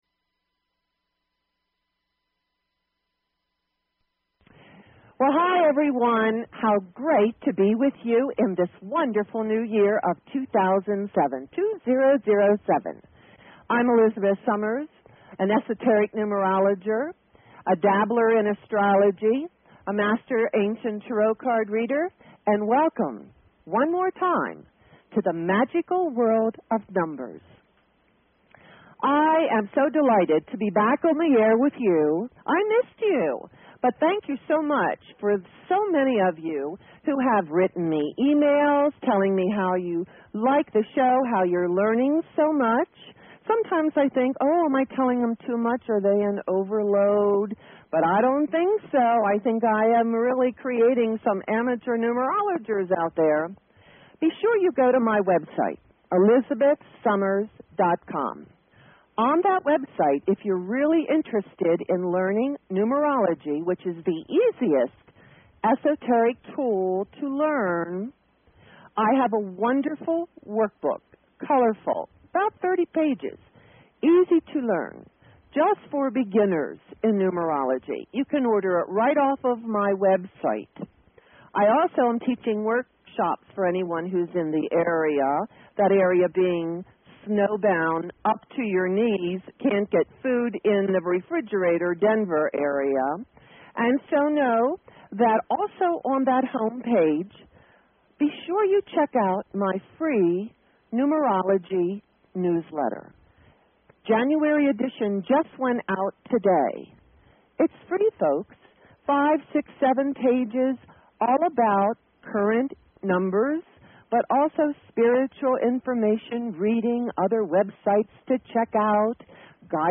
Talk Show Episode, Audio Podcast, Magical_World_of_Numbers and Courtesy of BBS Radio on , show guests , about , categorized as